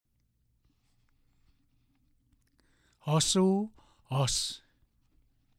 1. Vowel contrasts
B. Listen to the difference between and o.